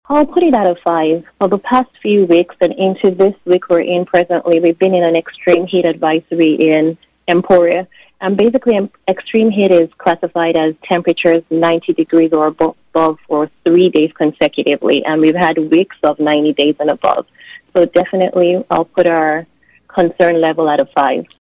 When asked on KVOE’s Newsmaker segment for her concern level, given the forecast into next week, Lyon County Health Officer Dr. Ladun Oyenuga said it’s maxed out on a five-point scale.